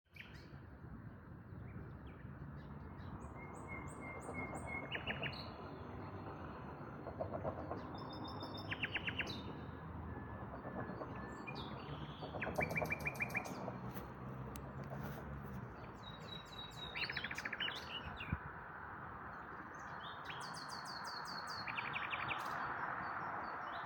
Im Ernst-Thälmann-Park hörte ich noch eine Nachtigall wunderschön singen.
Liegt das vielleicht an dem lauten Straßenverkehr warum die Nachtigallen im Ernst-Thälmann-Park so laut singen?
Nachtigall-Ernst-Thaelmann-Park.mp3